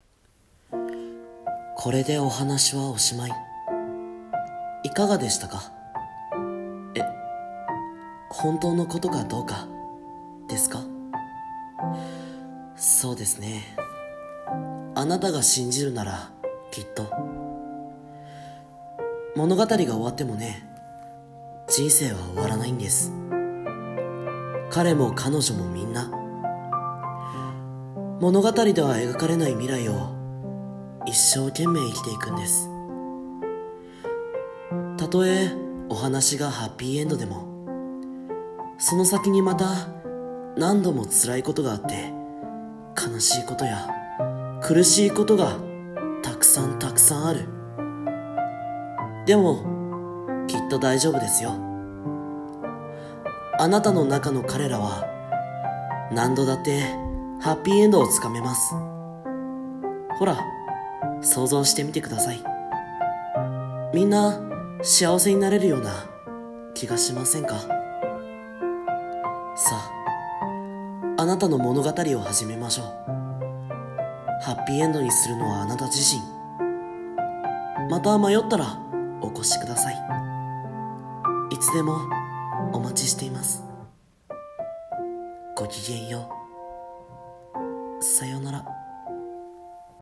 朗読台本「エンドロールにて」